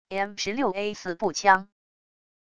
M16A4步枪wav音频